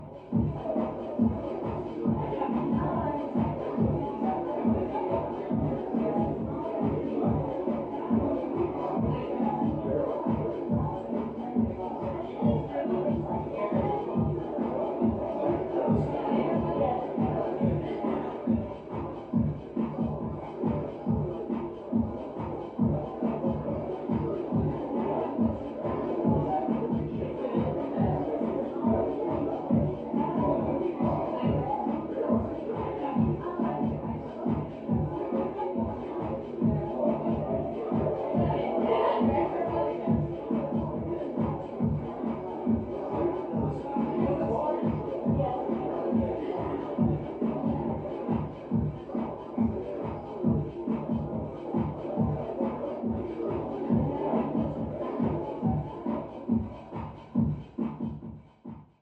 8. У соседей по квартире вечеринка